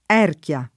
[ $ rk L a ]